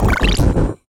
Sfx Platform No Fuel Sputter Fall Sound Effect
sfx-platform-no-fuel-sputter-fall.mp3